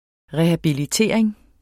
Udtale [ ˈʁε- ]